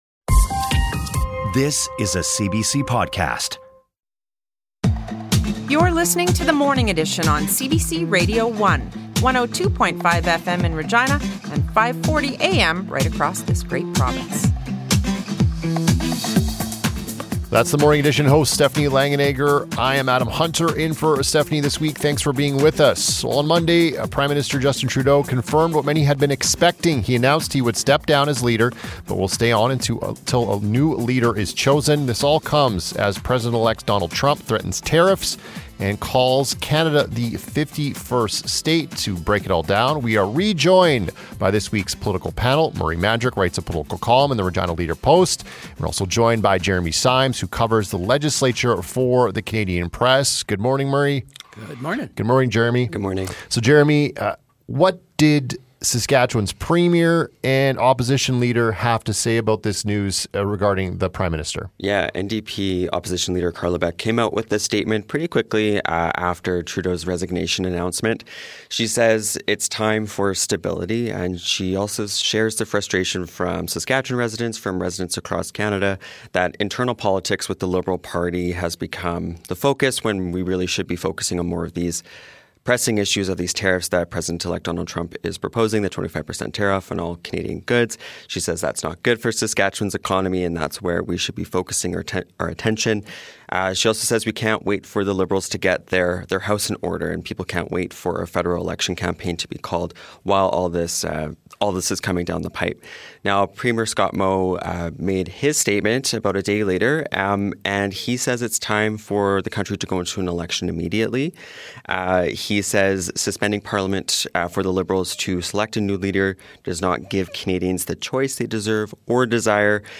The Morning Edition is Regina’s #1 rated morning show. We connect you with the people, news, culture that make this city and province great. Join the conversation weekdays from 6:00 to 8:30am on CBC Radio One.